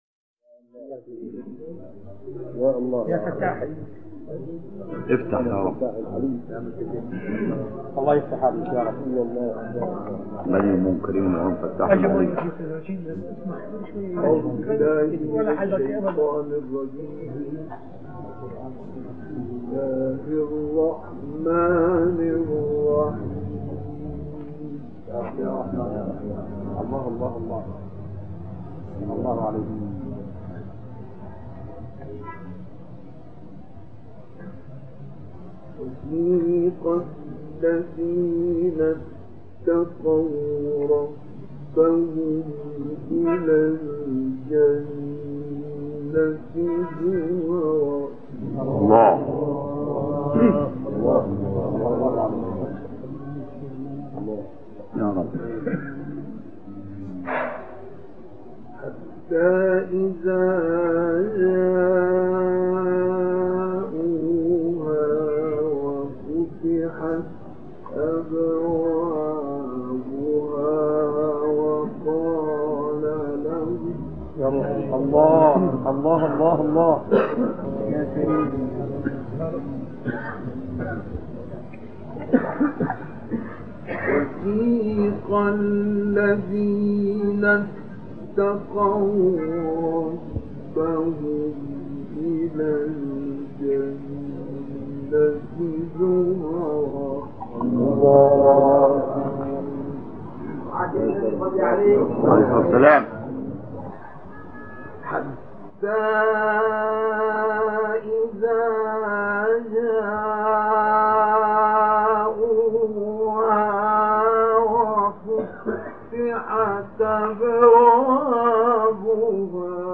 মিশরের প্রসিদ্ধ ক্বরী 'মুস্তাফা ইসমাইলে'র কুরআন তিলাওয়াত
আন্তর্জাতিক ডেস্ক: বিশ্ব বিখ্যাত ক্বারি মুস্তাফা ইসমাইলের সূরা যুমার ও হুজরাতের কিছু আয়াতের তিলাওয়াত সামাজিক নেটওয়ার্কে প্রকাশ পেয়েছে।
মুস্তাফা ইসমাইল তার সিরিয়া সফরে এক কুরআন মাহফিলে যুমার সূরার ৩৭ নম্বর এবং হুজরাতের ৭ ও ৮ নম্বর আয়াত তিলাওয়াত করেছেন।